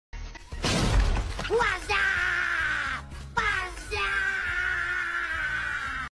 Pac Man Screams WAZZAP at Smiling Friends